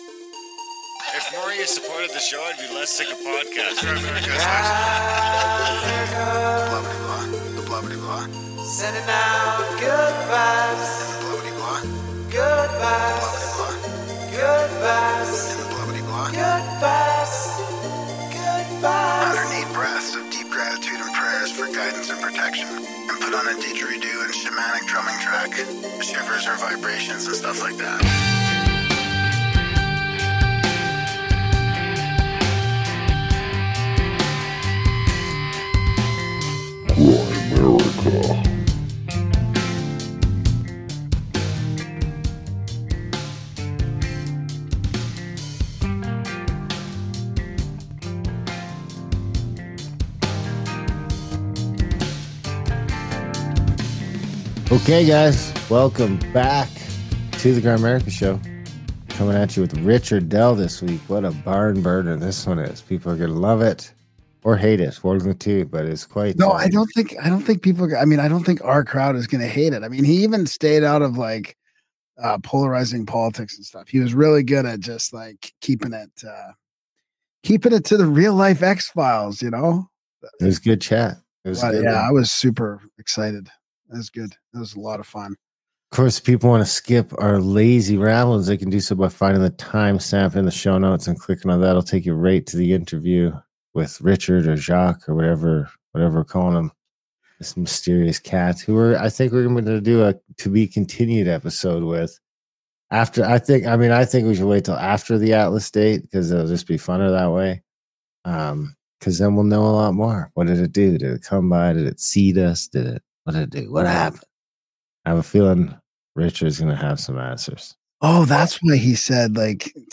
Interview starts at 26:35